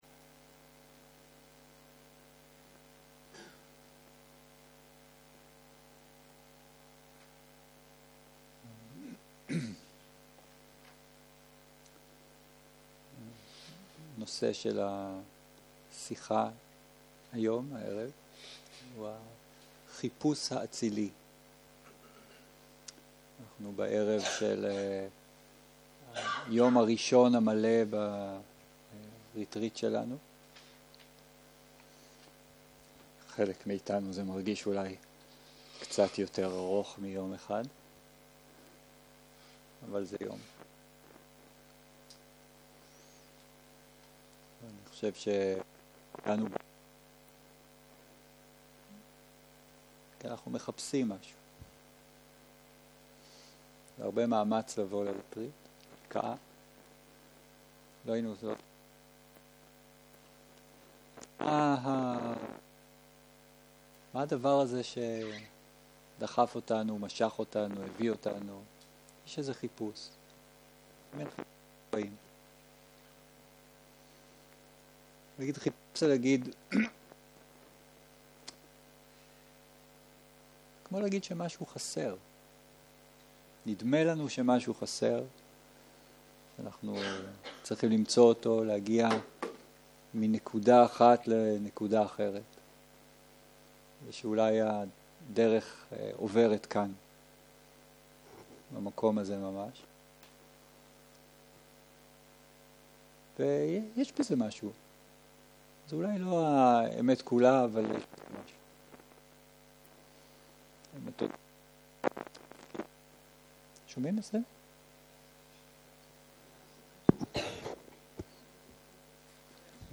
ום 2 - ערב - שיחת דהרמה - החיפוש האצילי - הקלטה 4